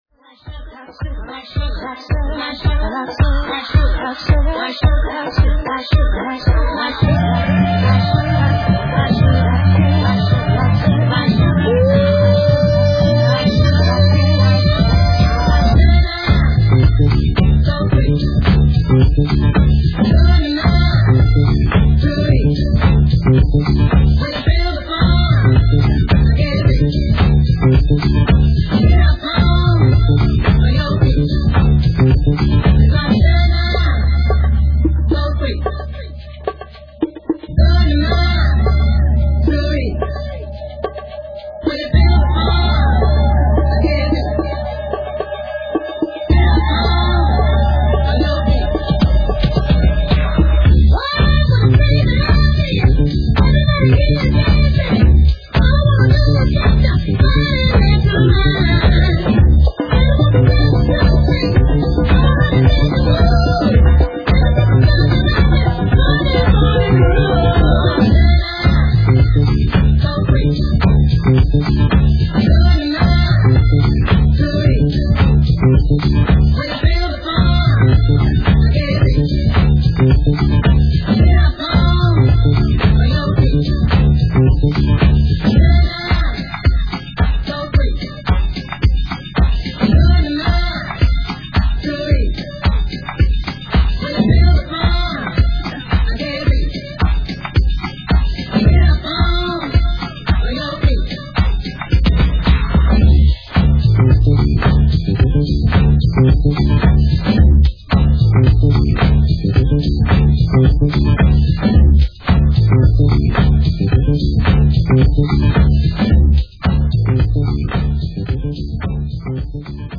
Remastered for best Dancefloor Satisfaction!